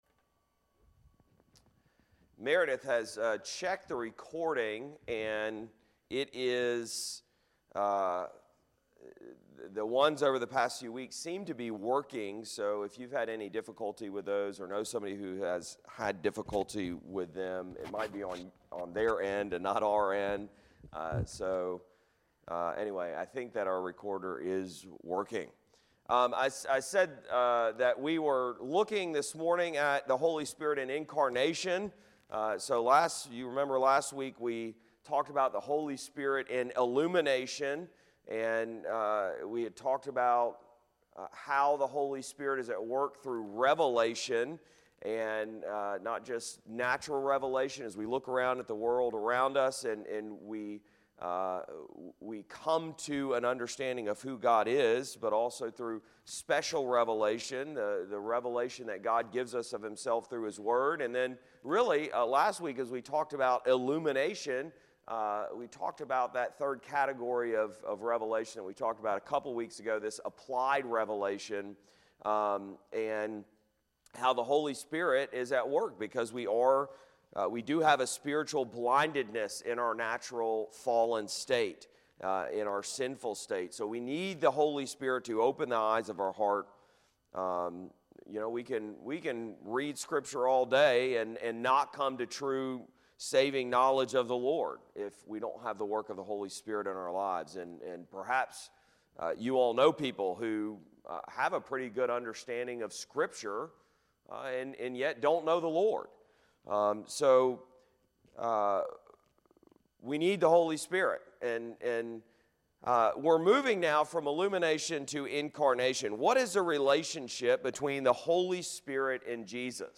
Listen to sermons from Covenant Presbyterian Church of Monroe, Louisiana.